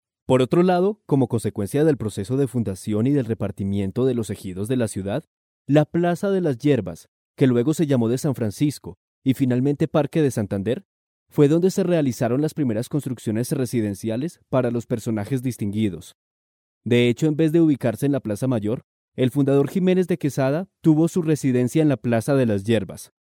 Colombian voice over. Colombian voice over agency
Colombian male voices